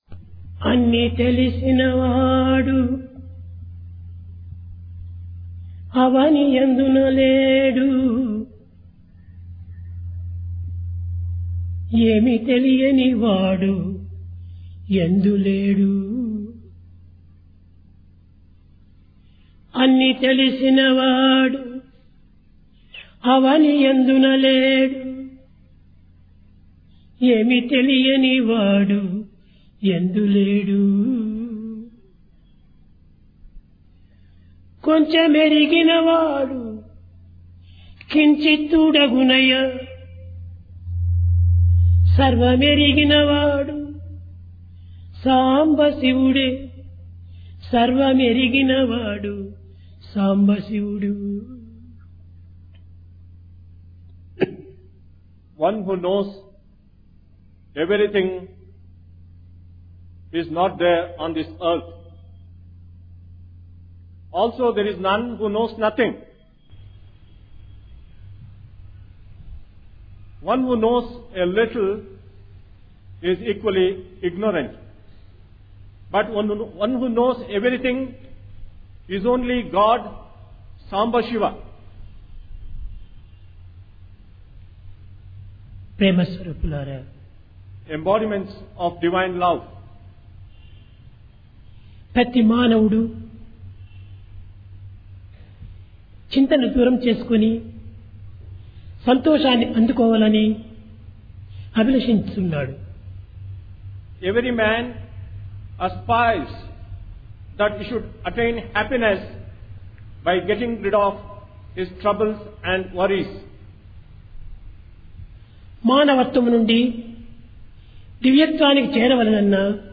Divine Discourse of Bhagawan Sri Sathya Sai Baba, Sri Sathya Sai Speaks, Vol 36 (2003)
Place Brindavan